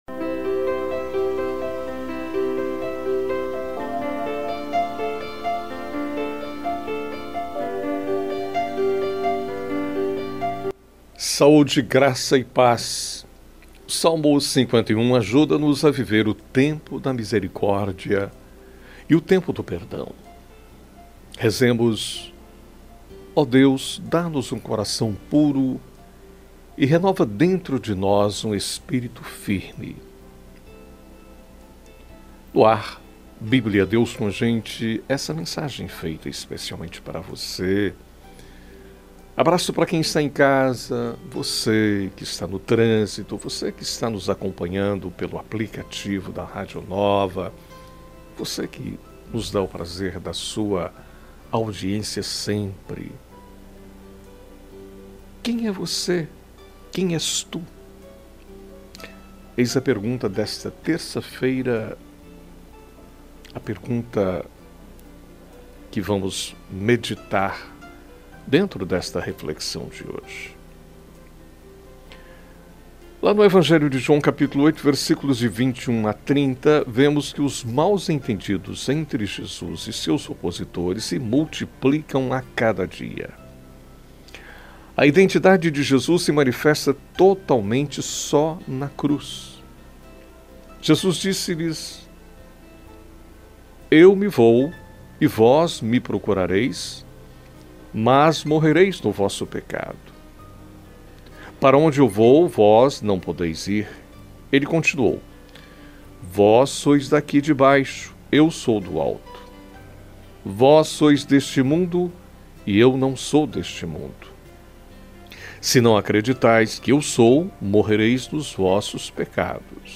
na voz do locutor